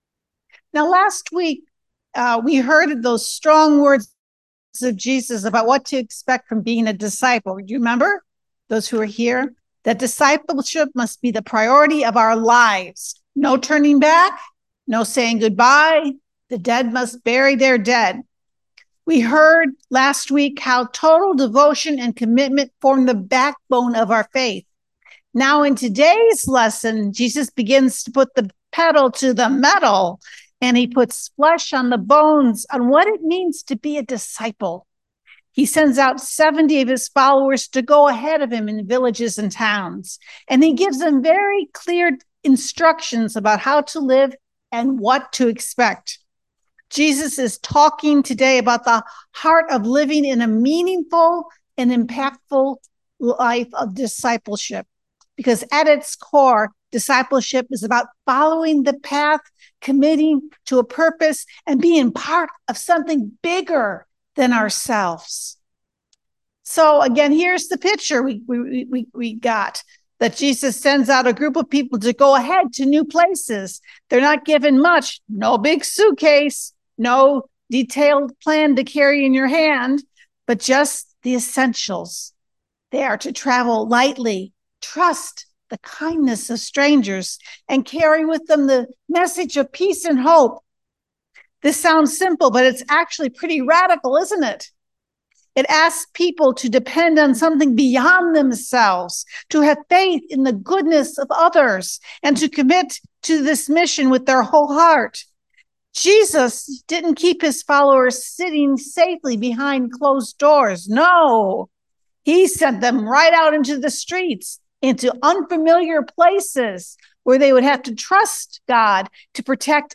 Preacher
Service Type: Sunday Morning